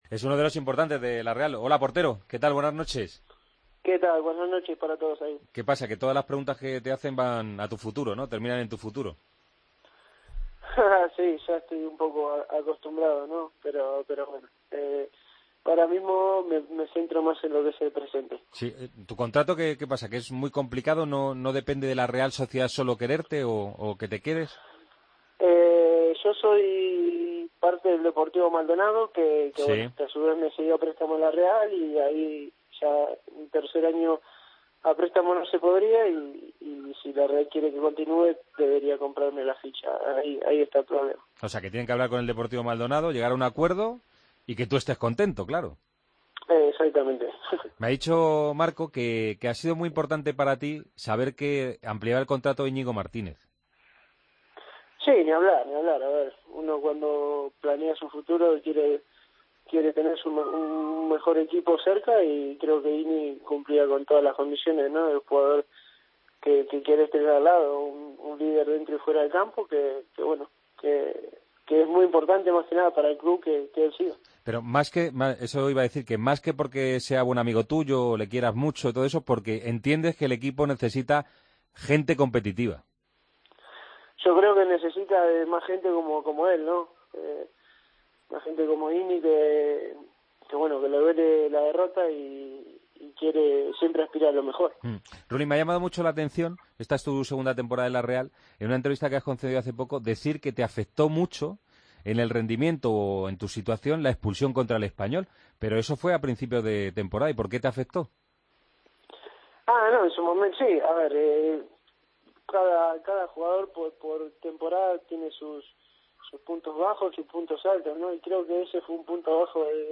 Redacción digital Madrid - Publicado el 30 abr 2016, 02:14 - Actualizado 18 mar 2023, 16:59 1 min lectura Descargar Facebook Twitter Whatsapp Telegram Enviar por email Copiar enlace El portero de la Real Sociedad habló en El Partido de las 12 antes de recibir al Real Madrid.